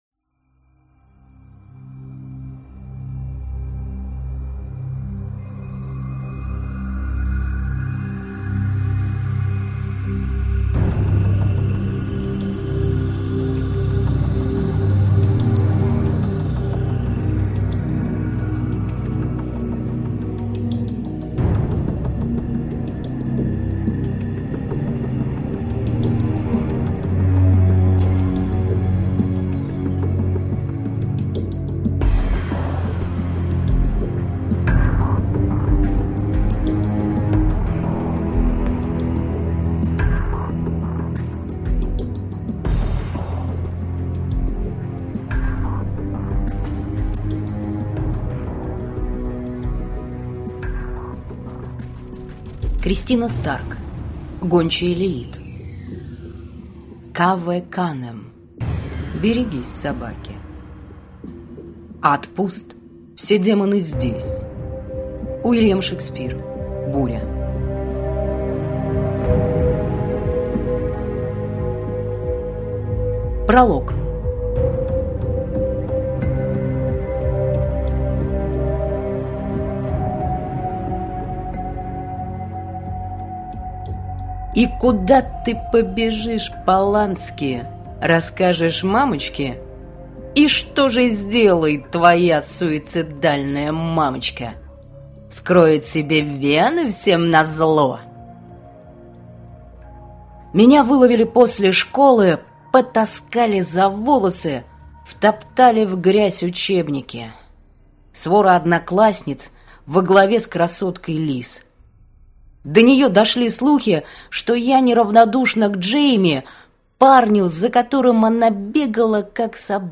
Аудиокнига «Гончие Лилит» в интернет-магазине КнигоПоиск ✅ Фэнтези в аудиоформате ✅ Скачать Гончие Лилит в mp3 или слушать онлайн